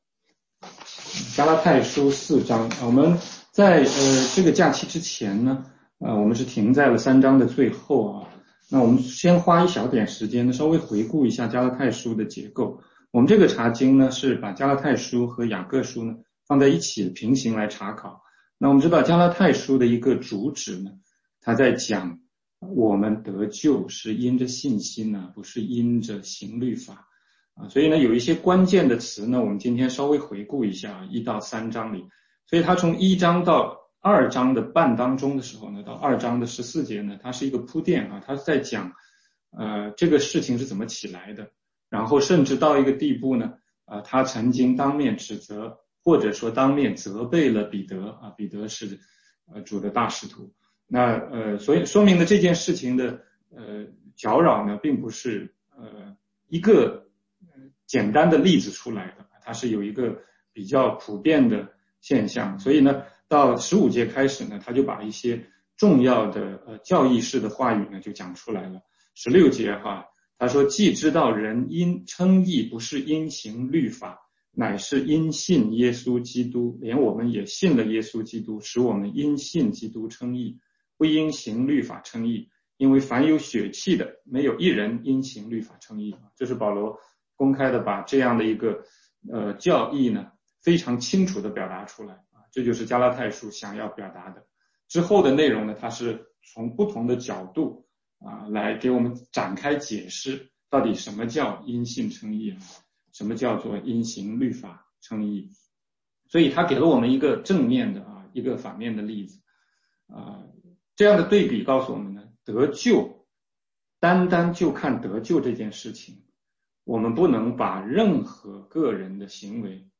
16街讲道录音 - 加拉太书4章1-11节：不要再归回那懦弱无用的小学了！